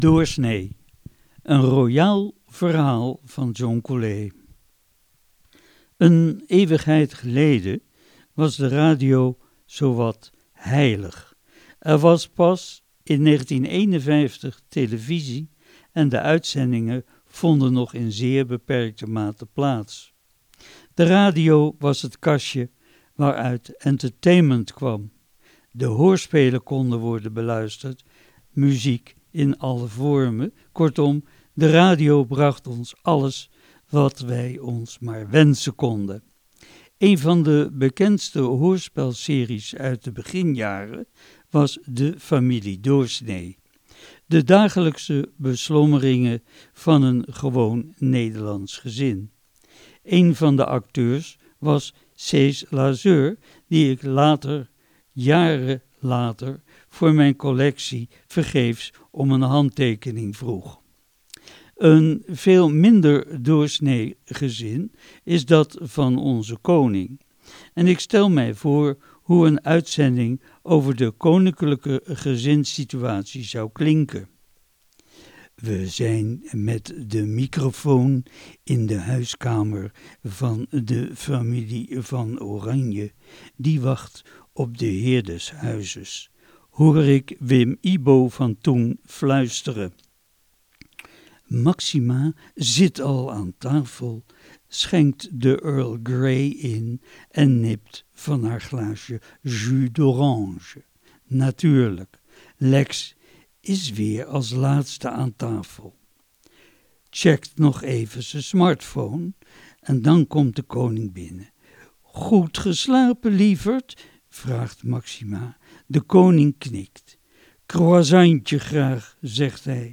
Column